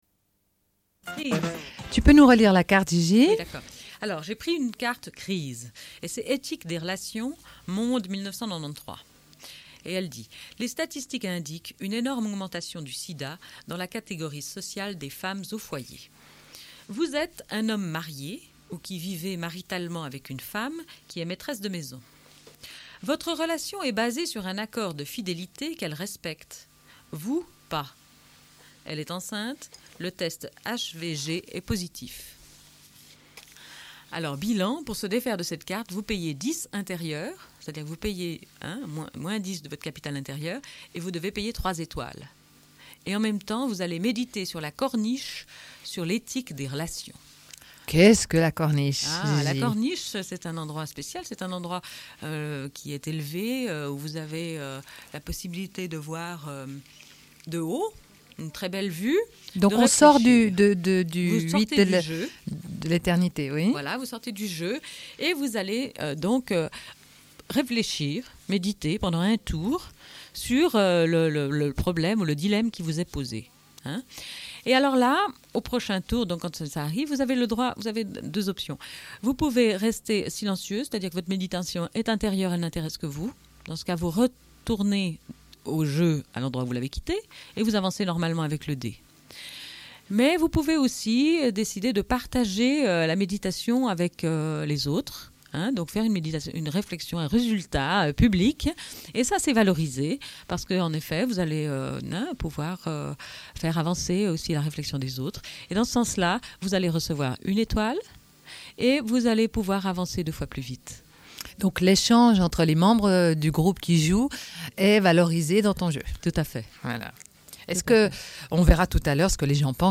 Une cassette audio, face B29:43